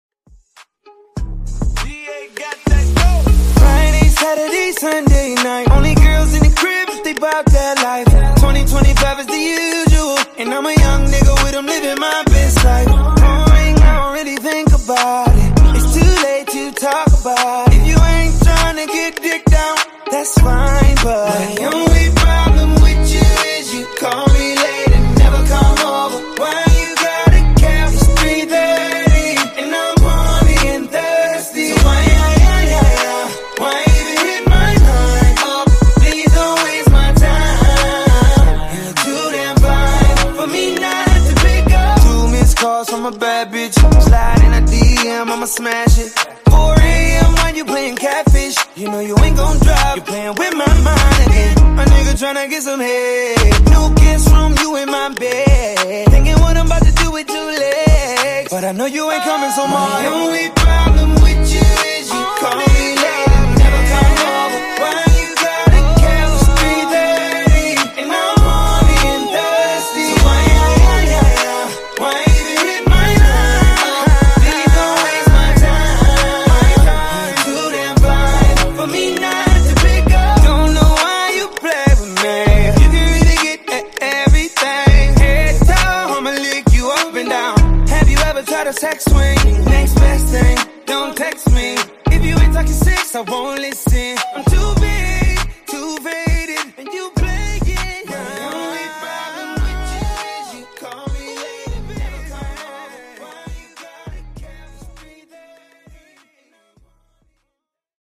Genres: R & B , RE-DRUM
Dirty BPM: 85 Time